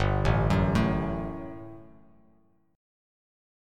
GM7sus2 chord